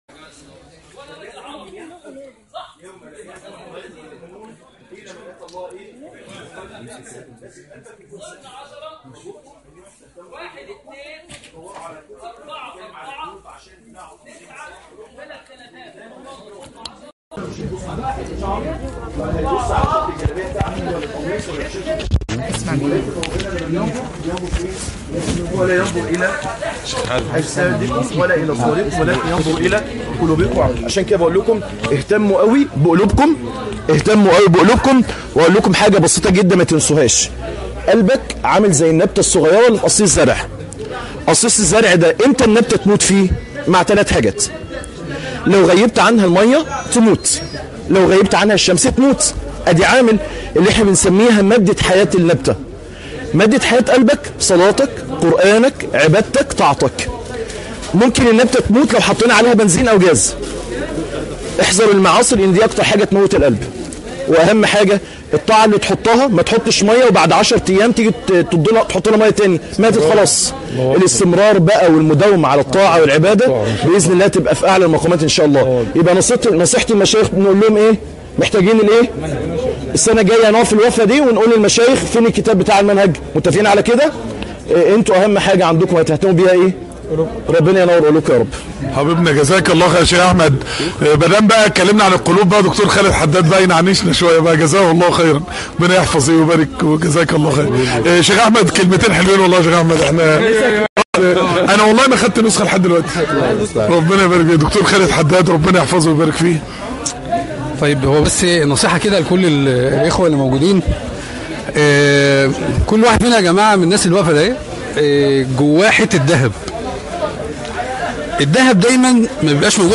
لقاء في معرض الكتاب